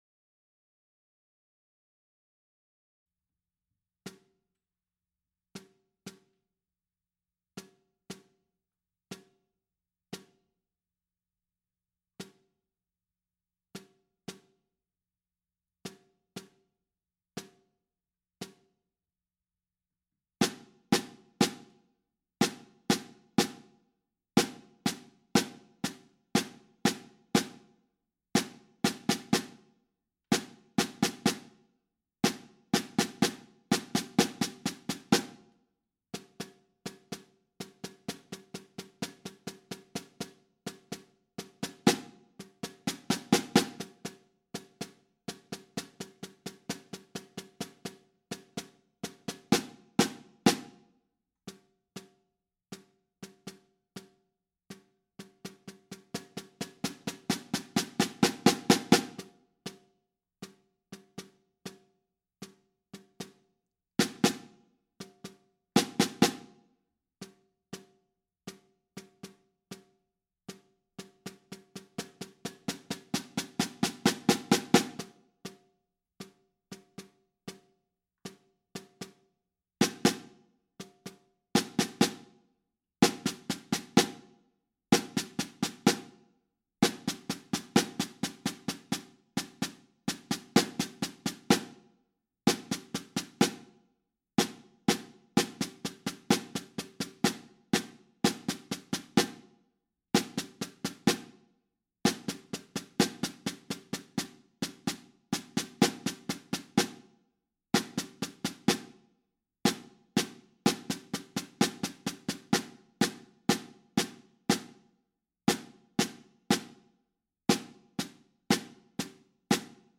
Voicing: Snare Drum